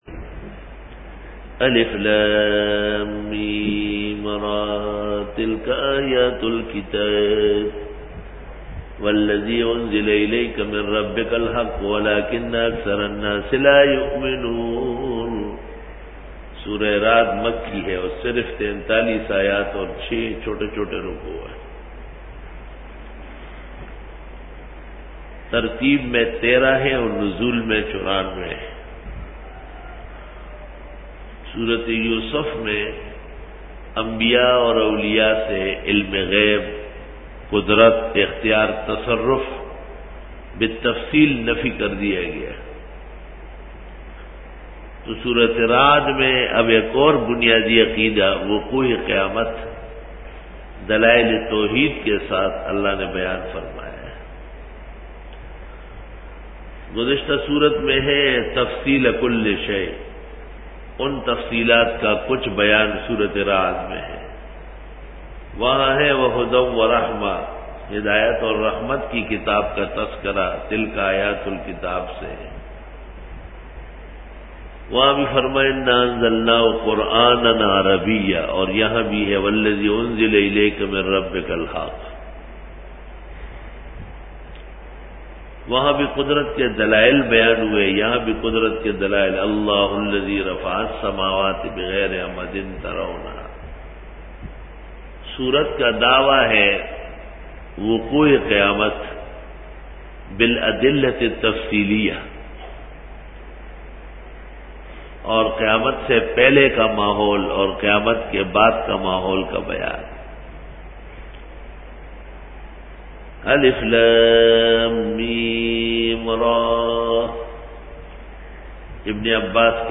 Dora-e-Tafseer 2009